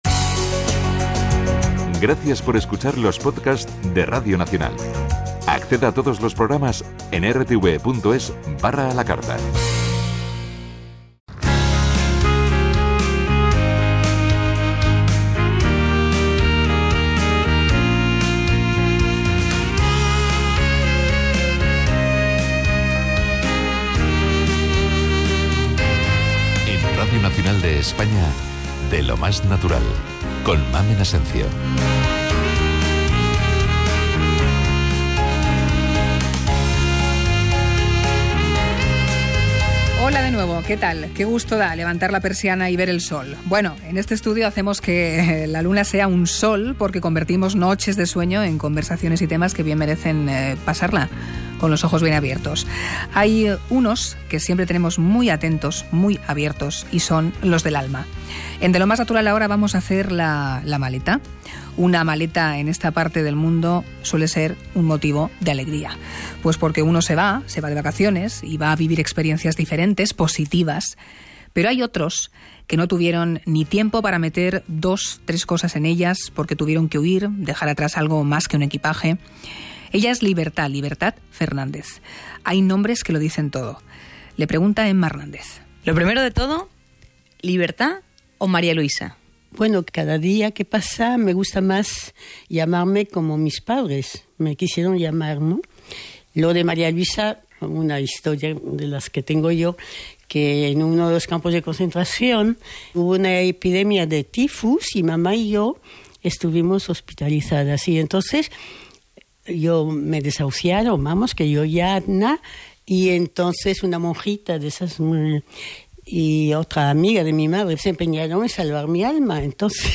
Reportaje en RNE sobre la Exposición 11 vidas en 11 maletas